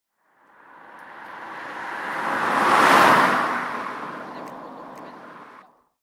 car